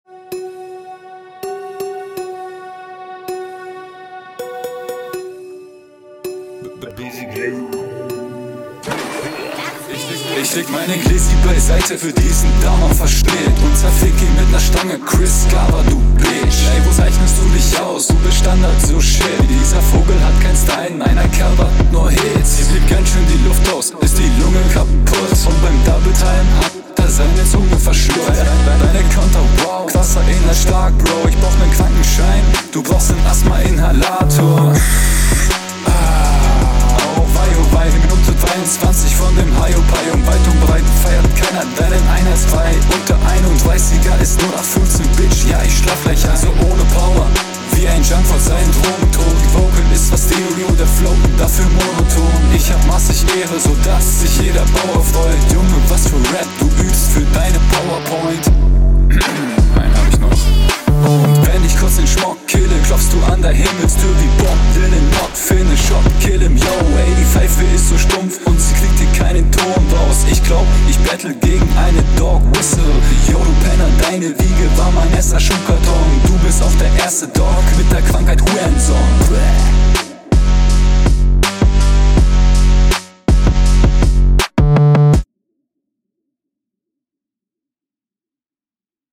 Flow hier auch nicht so dolle.
Was ist das für ein Mix bro Aber ist ansonsten ganz cool